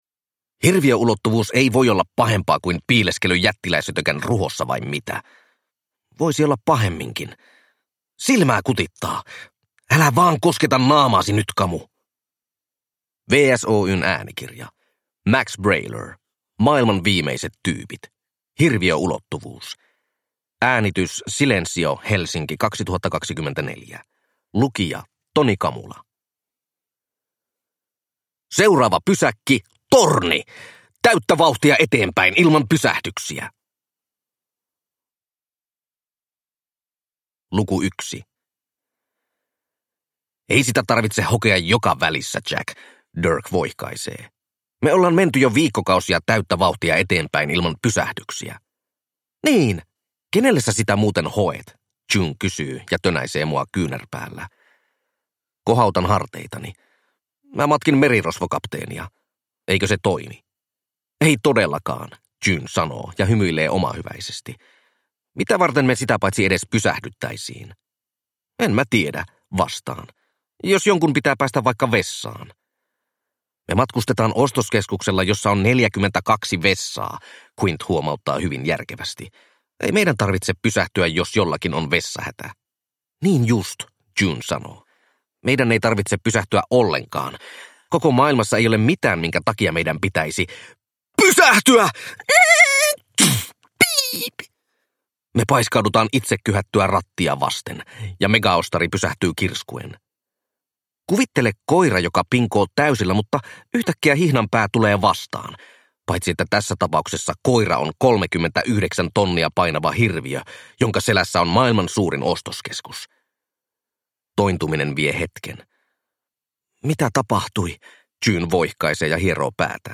Maailman viimeiset tyypit: Hirviöulottuvuus – Ljudbok